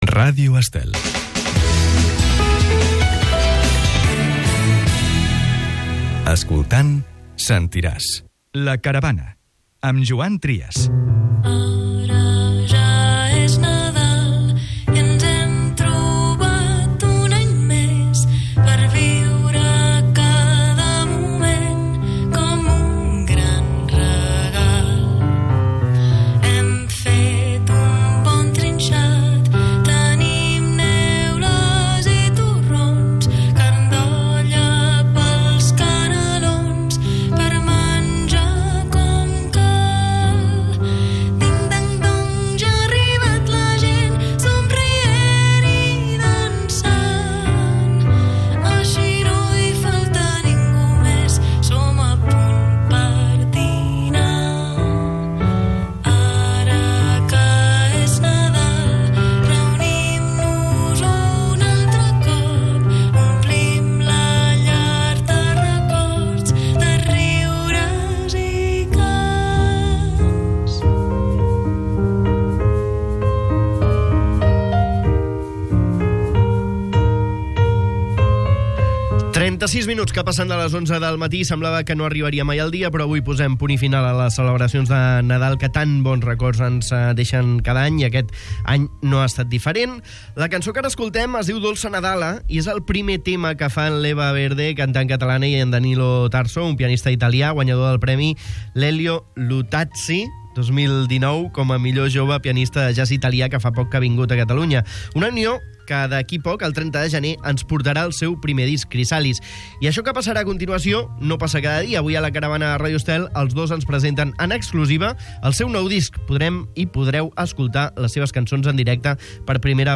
Chrysalis és "eclèctic", amb un fil conductor "oníric". En aquesta entrevista al programa La Caravana, el duet explica com es va conèixer i com van decidir treballar-hi plegats.